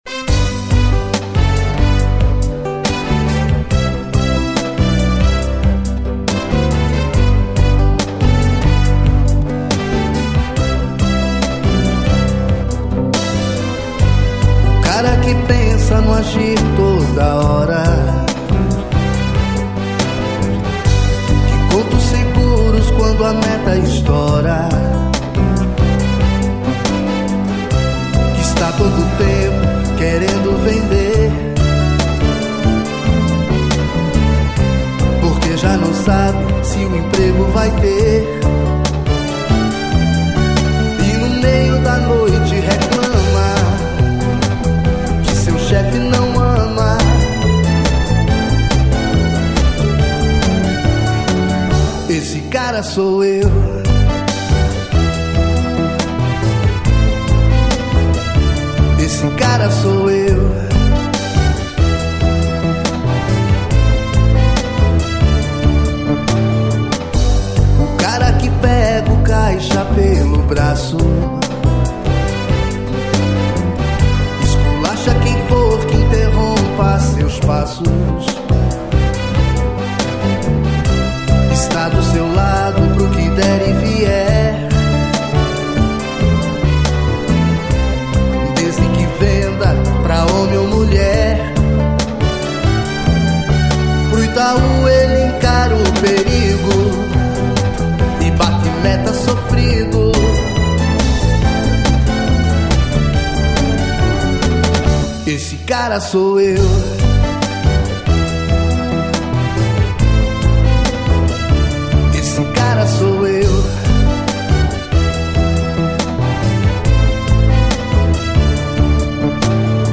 fazendo uma paródia sobre as condições de trabalho no banco.